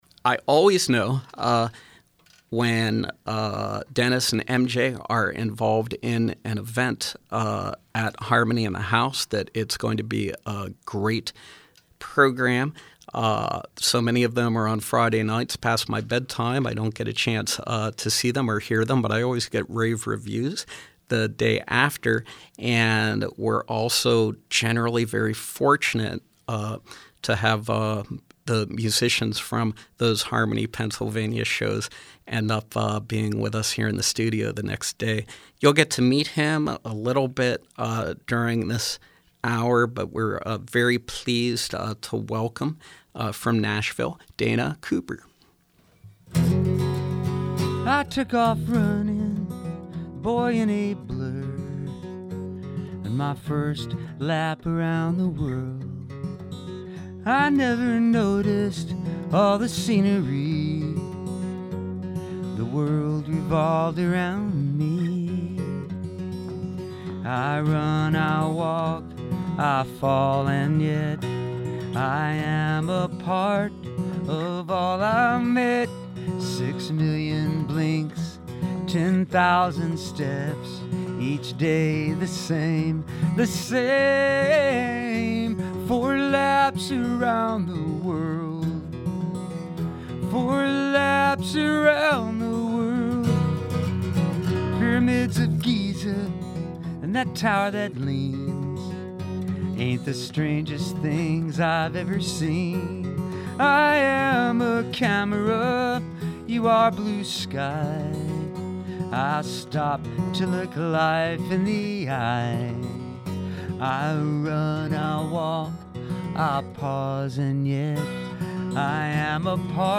Nashville based singer/songwriter
in studio.